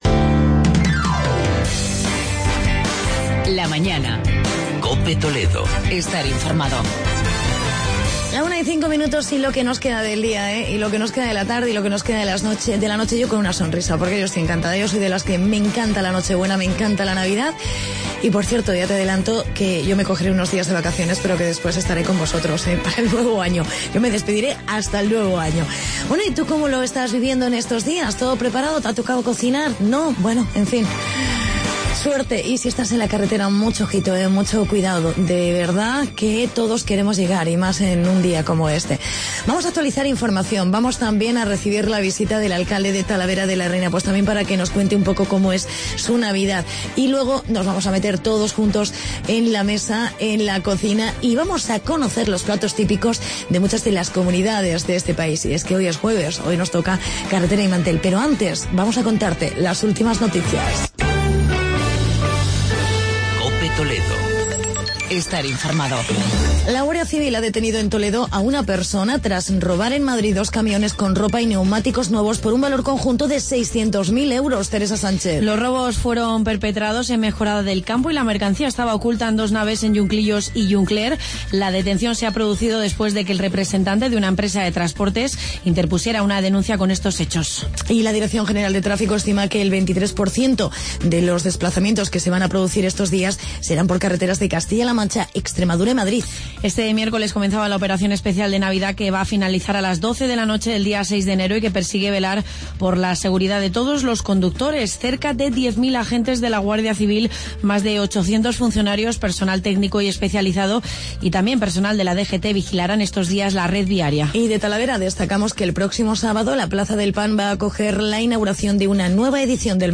Entrevista con el alcalde de Talavera